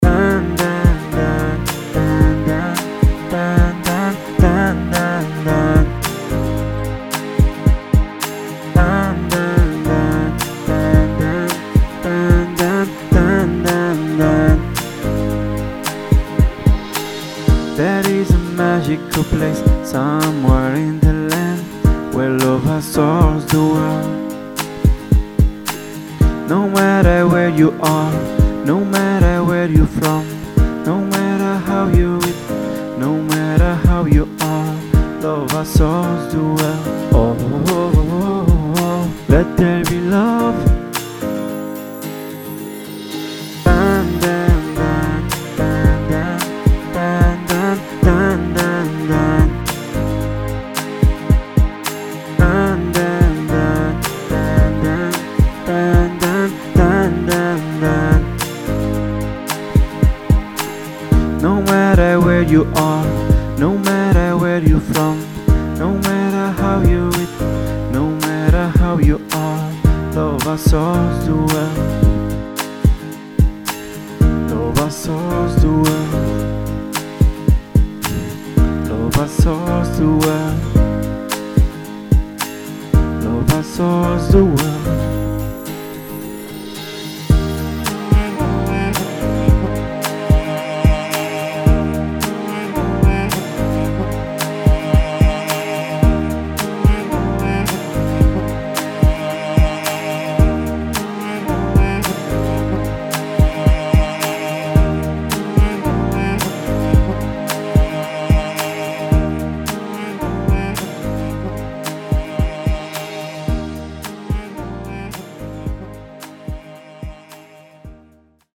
demo version